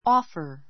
offer 中 A2 ɔ́ːfər オ ーふァ 動詞 提供する, 差し出す, 勧 すす める; 提案する; ～しようと申し出る offer a plan offer a plan ある計画を出す offer to help offer to help 手伝いましょうと申し出る I offered her my seat.=I offered my seat to her.